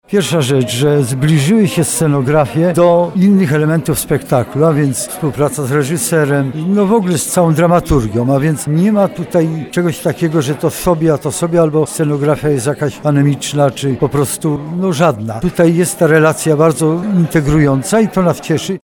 Festiwal podsumował Profesor Leszek Mądzik, Dyrektor Artystyczny Festiwalu.